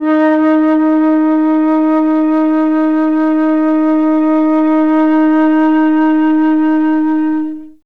51c-flt02-D#3.wav